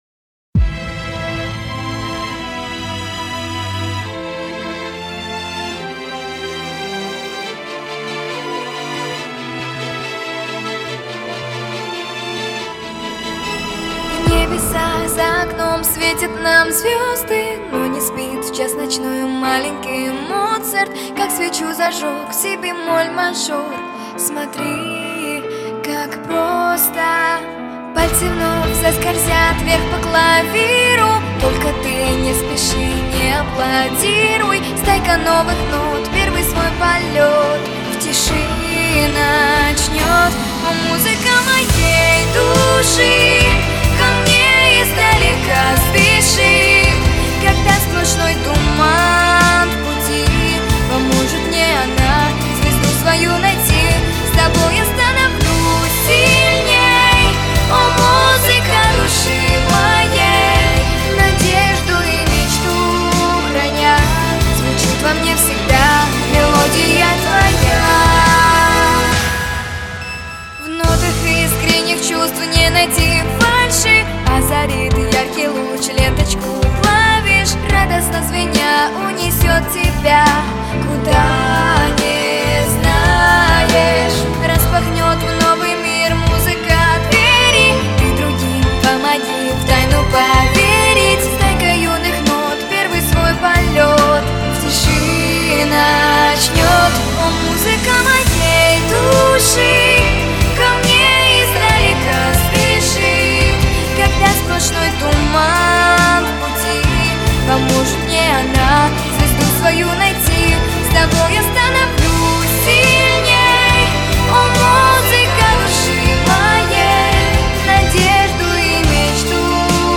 Характер песни: позитивный, лирический.
Темп песни: медленный.
Диапазон: До первой октавы - Ми второй октавы.